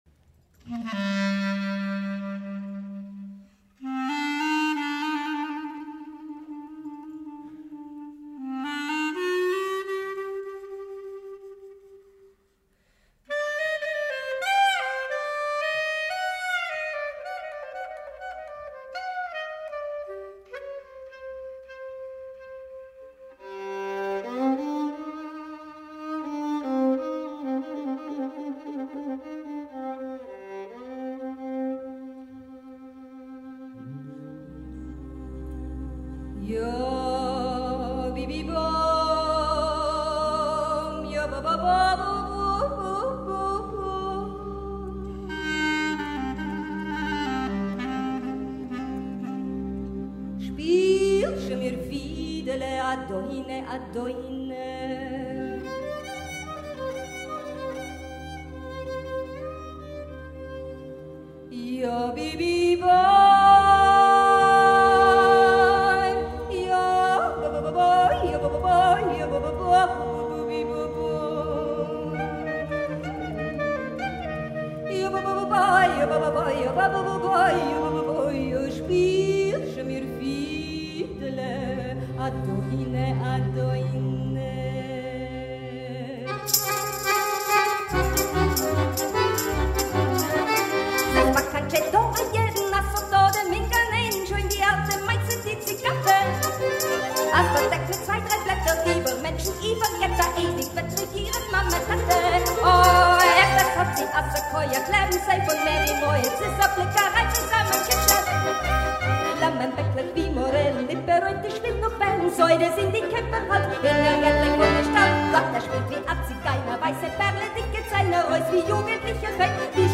KLezmermusik und jiddische Lieder